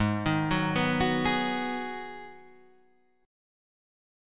G#m7 chord